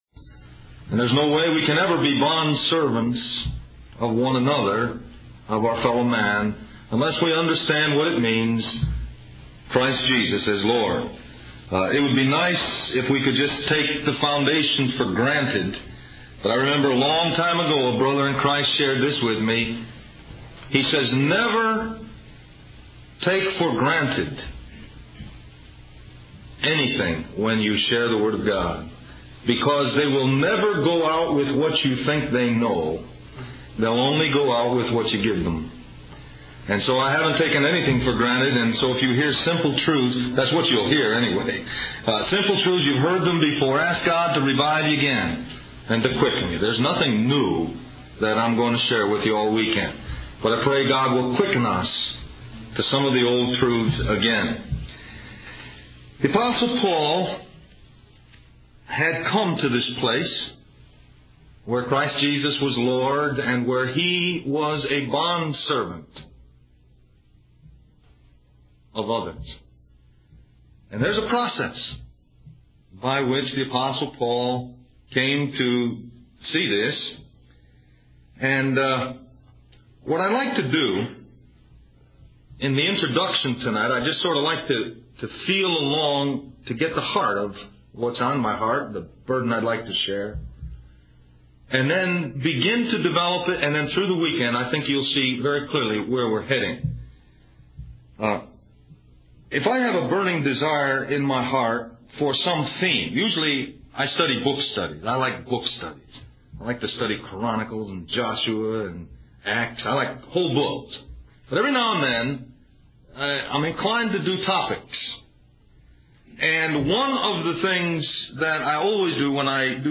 Del-Mar-Va Men's Retreat